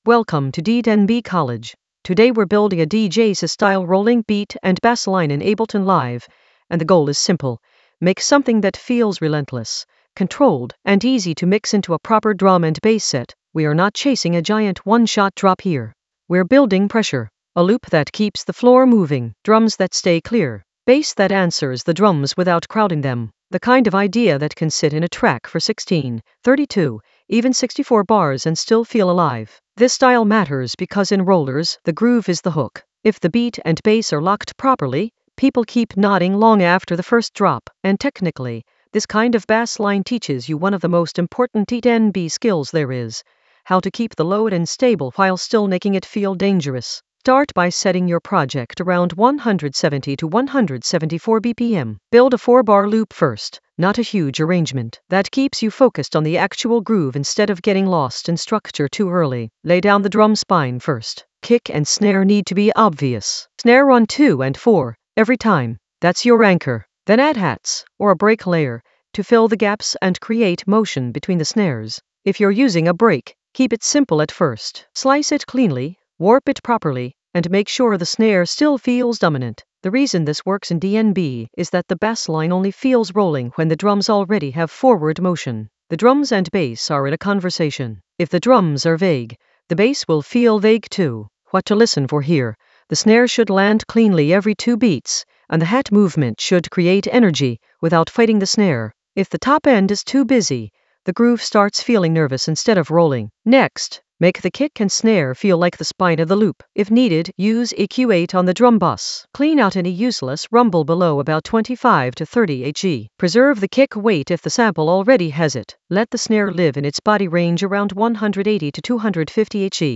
An AI-generated beginner Ableton lesson focused on DJ SY rolling beats and bassslines in the Basslines area of drum and bass production.
Narrated lesson audio
The voice track includes the tutorial plus extra teacher commentary.